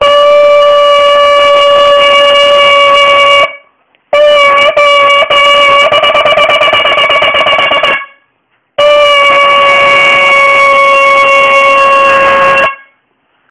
Complimentary Rosh Hashana Shofar Blast Ringtone
Rosh Hashana Shofar BlastÂ (.aac)
shofar_blast.aac